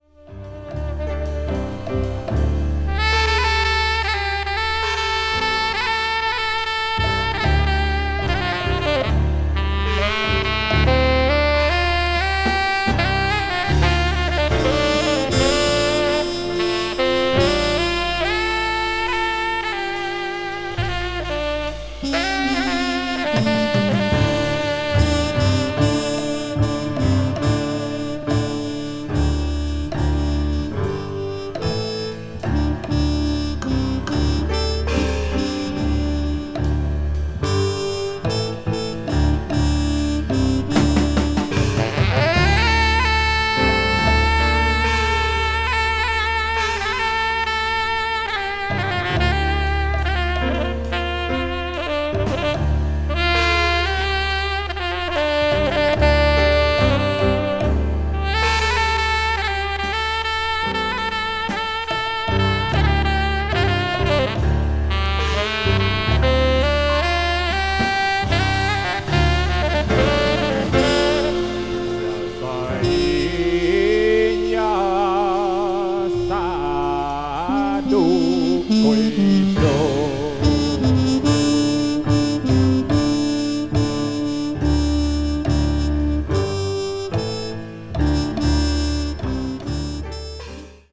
media : EX/EX(わずかにチリノイズが入る箇所あり)
jazz groove   post bop   spiritual jazz